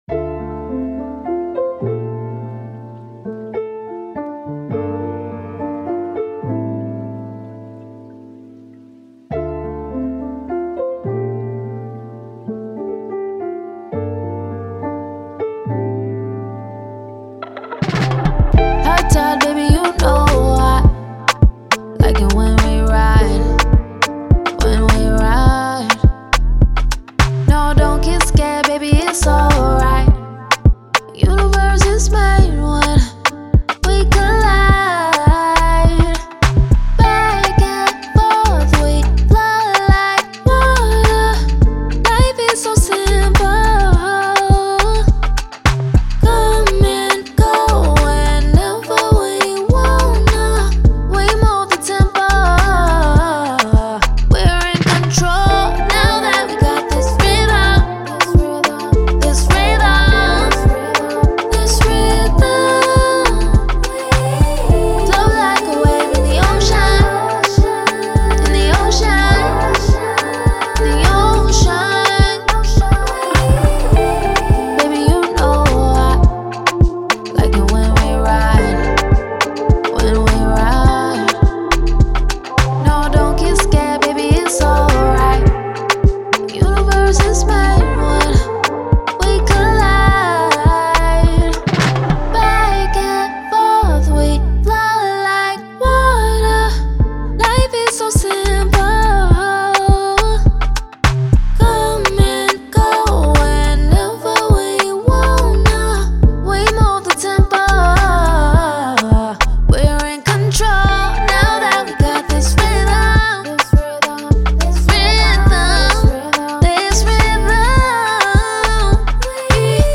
R&B
D Minor